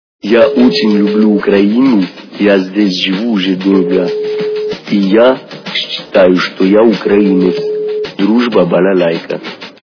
» Звуки » Люди фразы » Голос негра - Я очень люблю украину, я здесь живу уже долго и я считаю, что я украинец
При прослушивании Голос негра - Я очень люблю украину, я здесь живу уже долго и я считаю, что я украинец качество понижено и присутствуют гудки.